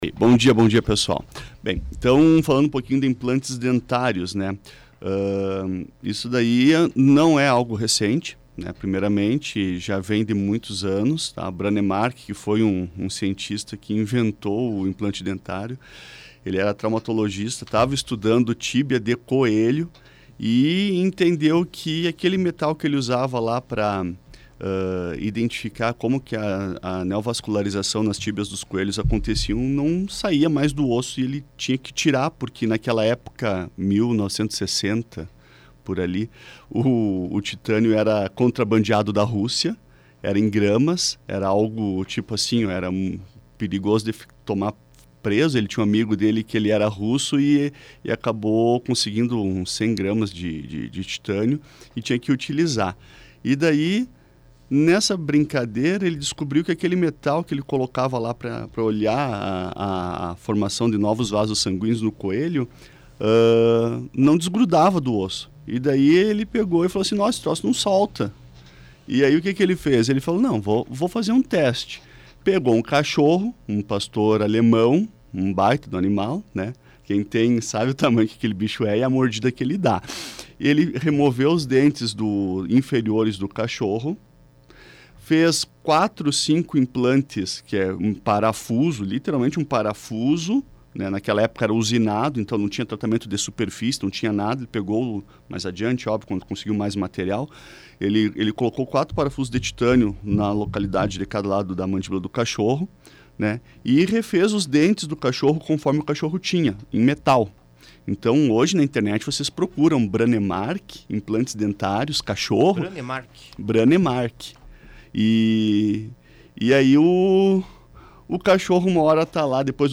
Foi ao ar nesta quinta-feira mais uma edição do quadro “Sorriso em Dia”, na Planalto News FM 92.1